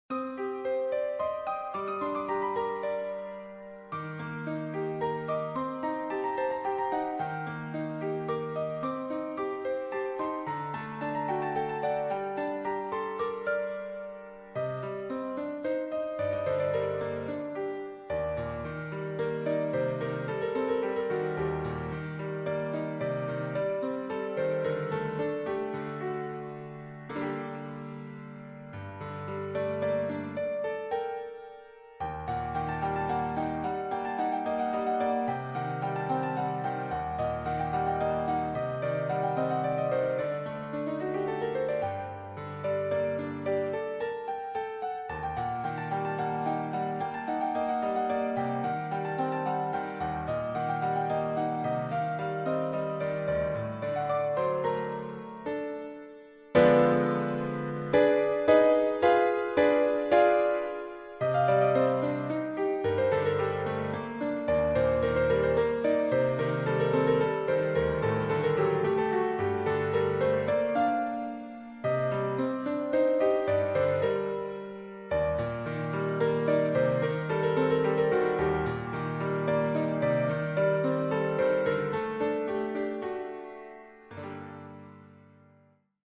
Müəllif: Azərbaycan Xalq Mahnısı
Melodiyası da ürəyə toxunan, axıcı və zərifdir.